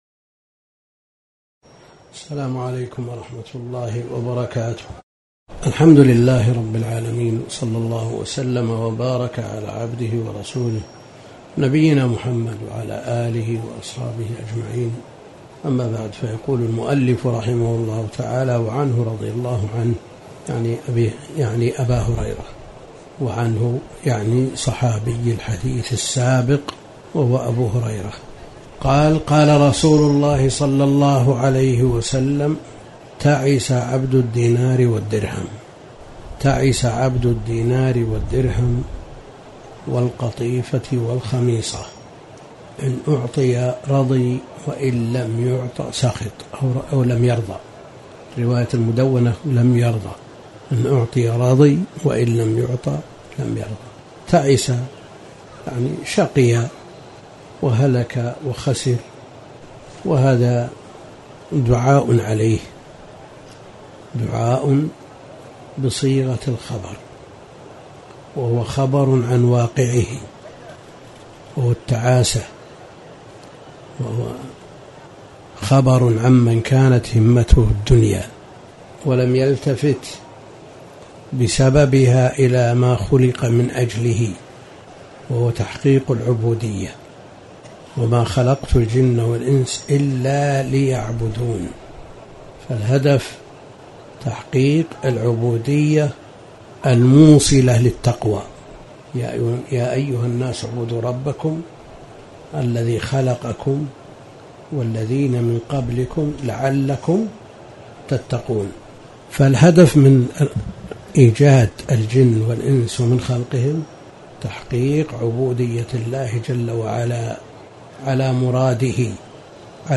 تاريخ النشر ١٣ ذو القعدة ١٤٣٨ هـ المكان: المسجد الحرام الشيخ: فضيلة الشيخ د. عبد الكريم بن عبد الله الخضير فضيلة الشيخ د. عبد الكريم بن عبد الله الخضير كتاب الجامع